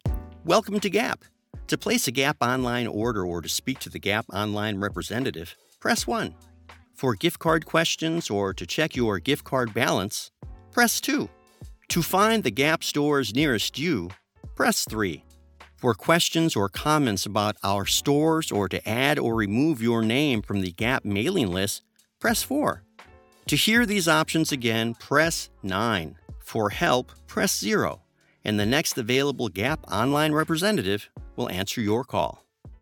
Telephony & IVR Voiceover
Warm. Clear. Built to Make Every Caller Feel Taken Care Of.
His delivery is clear and articulate without ever sounding robotic, helping callers stay engaged instead of frustrated.
Telephony & IVR Demos